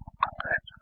This is the room where we recorded the evp's. This room is almost directly accross from room 410, the haunted Mary Lake Room.
EVP's
QA-QR400-3_CLR - Go Bed.wav